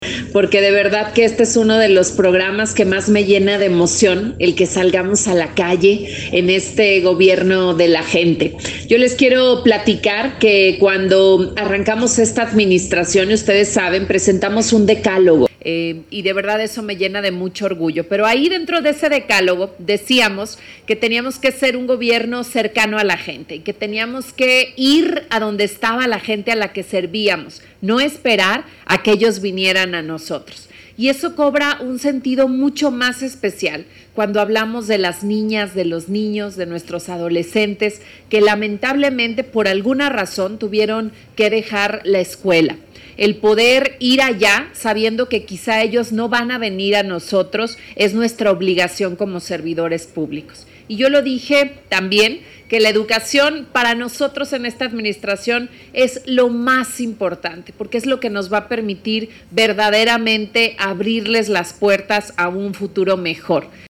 Así lo dijo la Gobernadora de Guanajuato, Libia Dennise García Muñoz Ledo, al iniciar la Jornada con Vocación para la Reinserción Escolar.
17-Octubre-Mensaje-de-la-Gobernadora-Libia-Dennise-Arranque-de-la-Jornada-con-Vocacion-para-la-Reinsercion-Escolar-.mp3